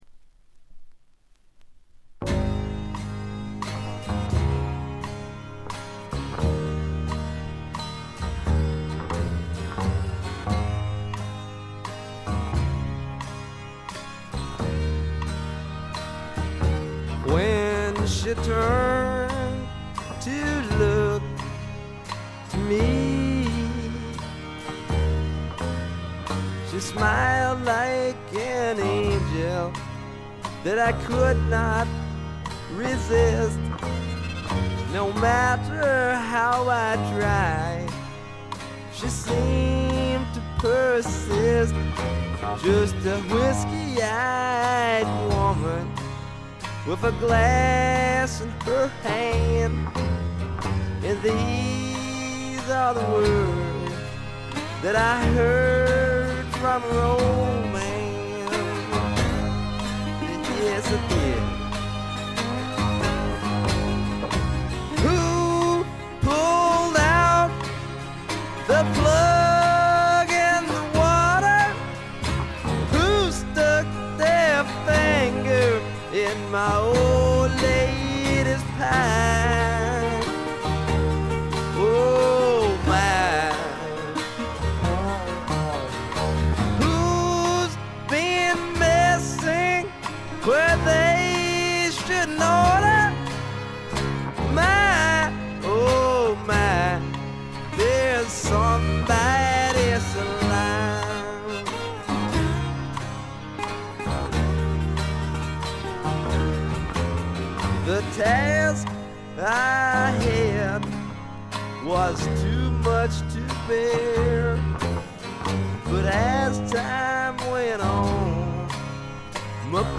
ほとんどノイズ感なし。
よりファンキーに、よりダーティーにきめていて文句無し！
試聴曲は現品からの取り込み音源です。